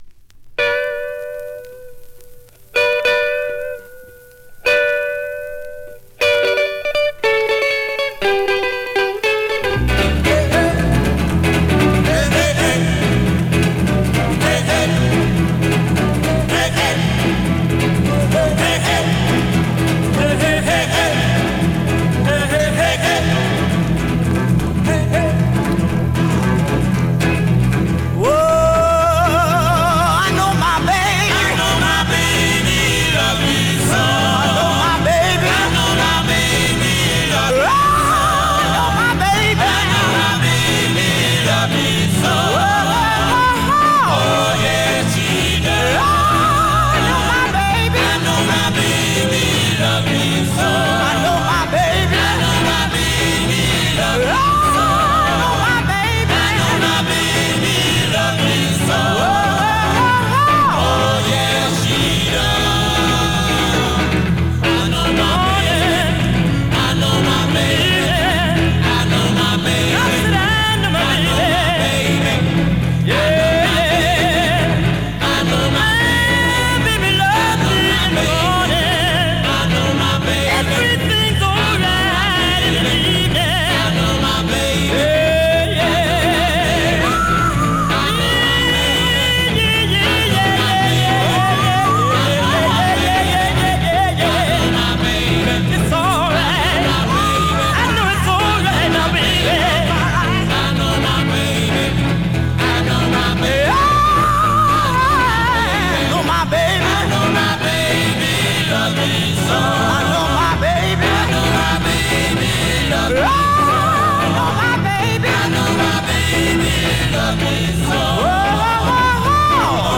Great up-tempo Rnb / Mod dancer with a gospel feel to it .
R&B, MOD, POPCORN